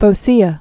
(fō-sēə)